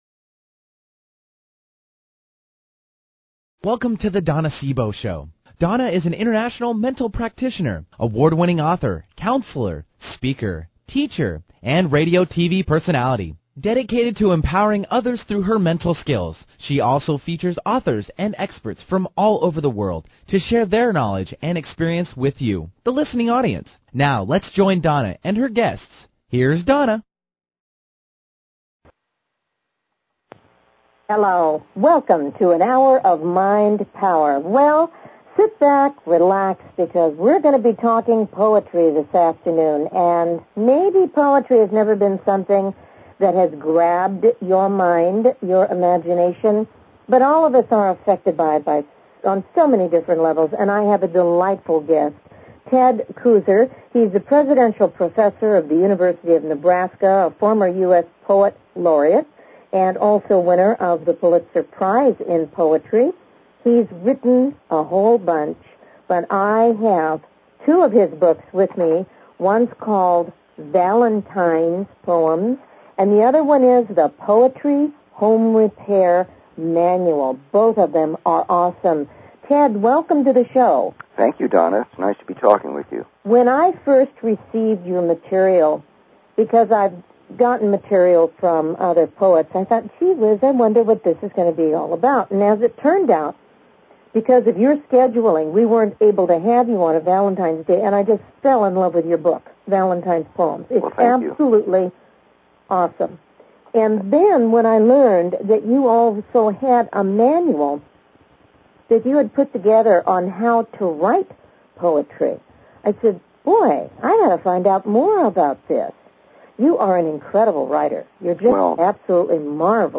Her interviews embody a golden voice that shines with passion, purpose, sincerity and humor.
Talk Show
Callers are welcome to call in for a live on air psychic reading during the second half hour of each show.